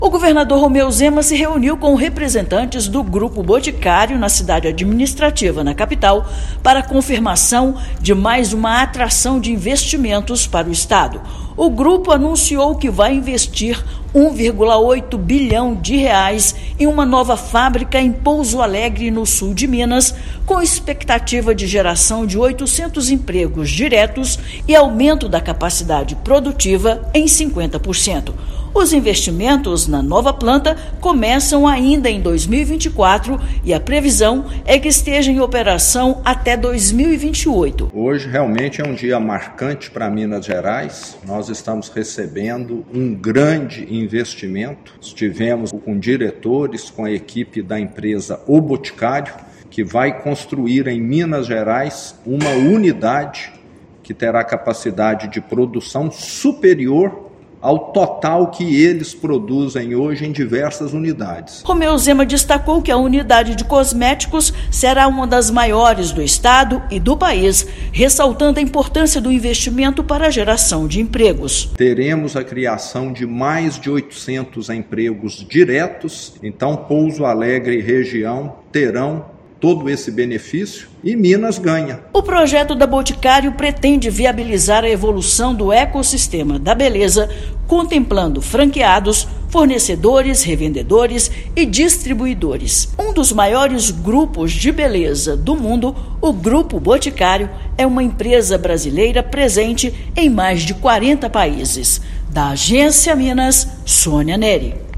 Projeto fomenta novas oportunidades de emprego na região de Pouso Alegre, conectando estratégia de negócio ao impacto positivo na sociedade. Ouça matéria de rádio.